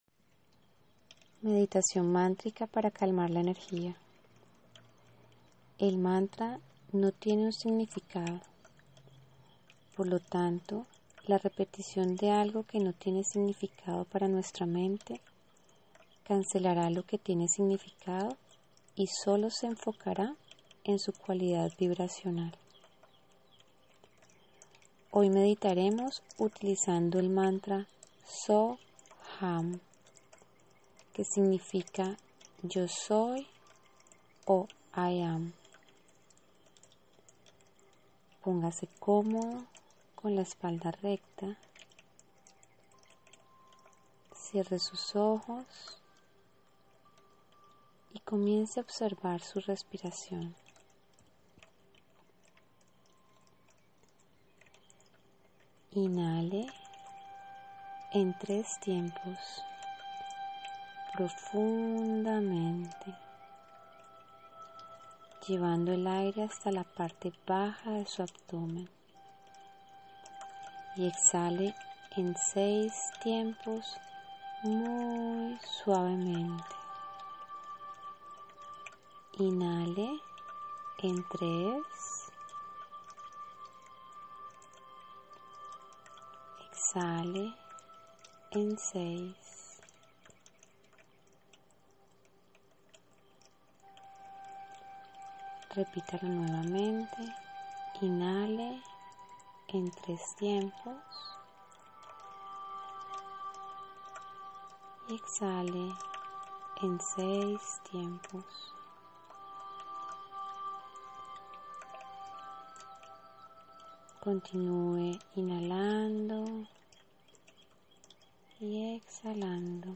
Meditacion-Mantrica-SoHam.mp3